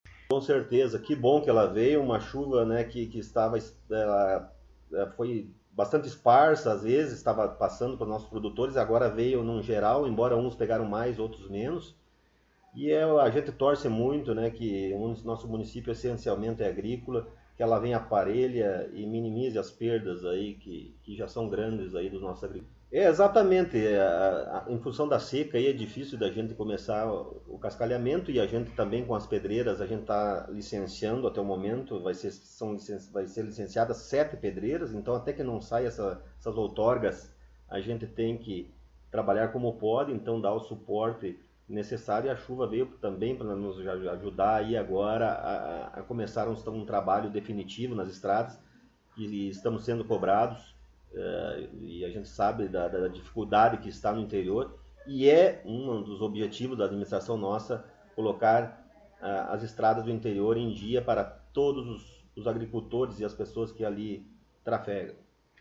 Prefeito Municipal Rodrigo Sartori concedeu entrevista